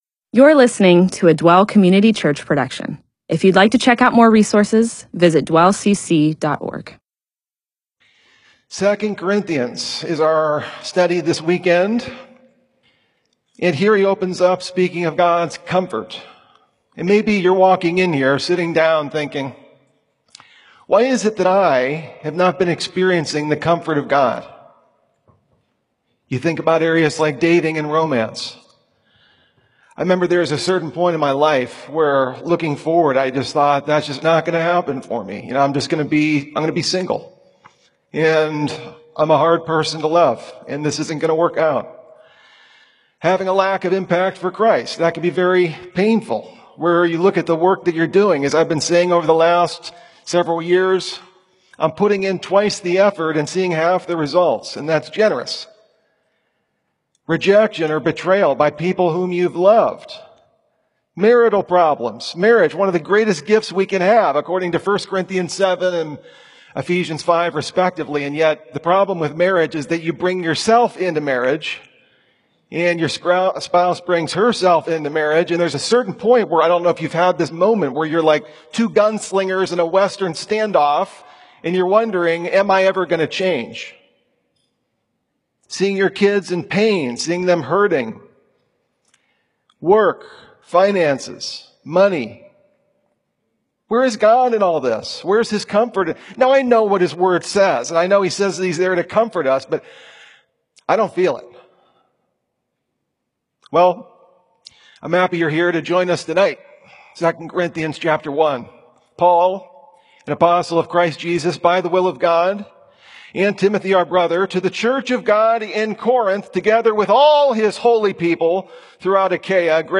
MP4/M4A audio recording of a Bible teaching/sermon/presentation about 2 Corinthians 1:1-11.